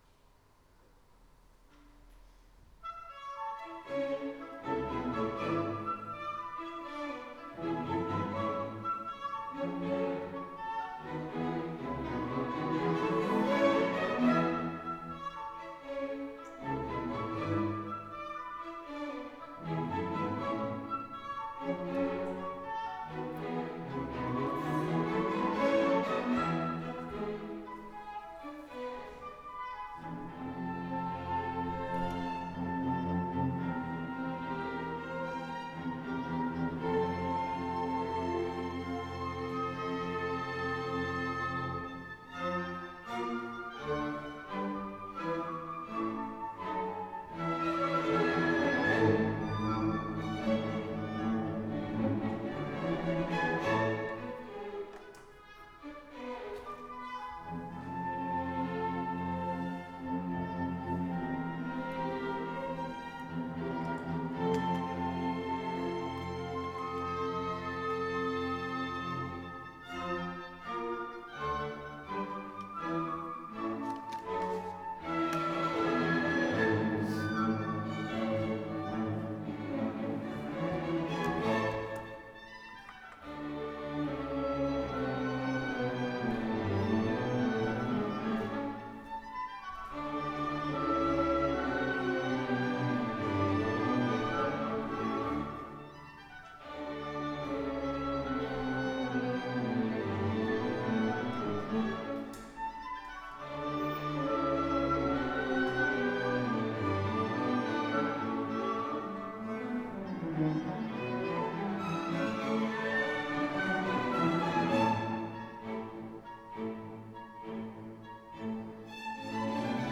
3-Saint-Saens-Sinfonie-A-Dur-Scherzo-vivace.wav